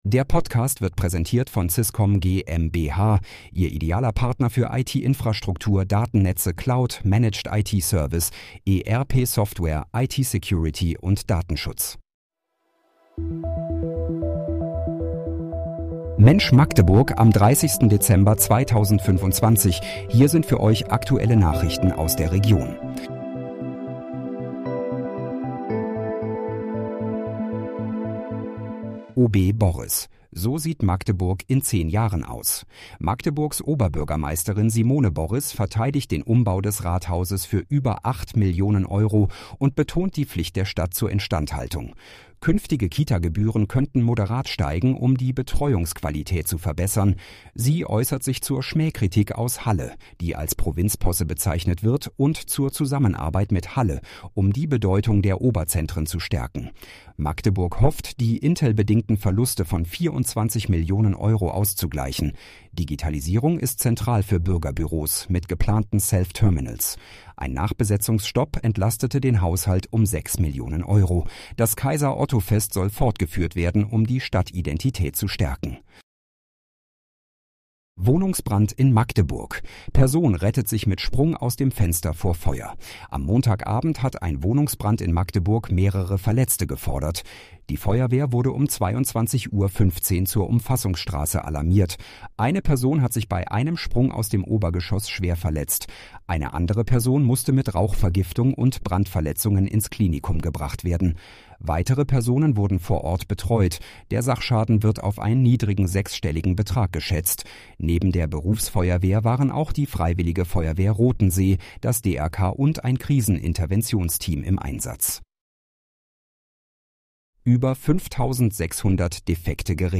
Mensch, Magdeburg: Aktuelle Nachrichten vom 30.12.2025, erstellt mit KI-Unterstützung